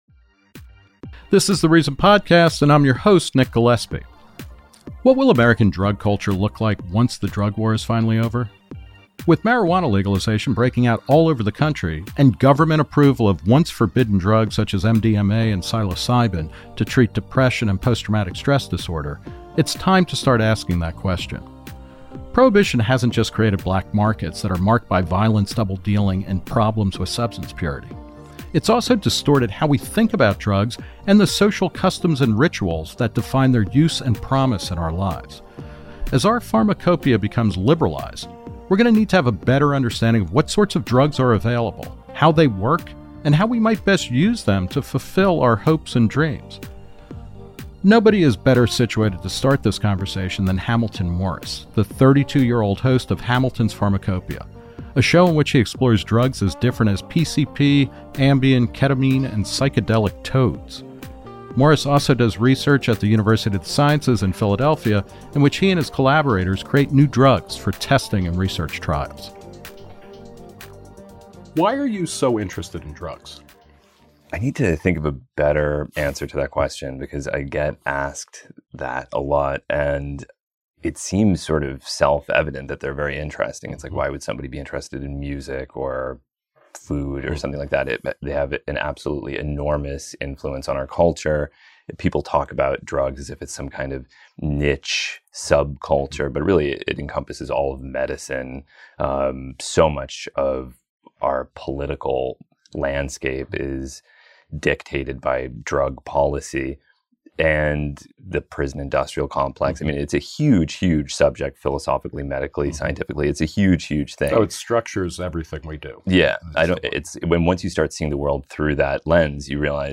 Nick Gillespie speaks with Viceland's Hamilton Morris about why he's so interested in drugs.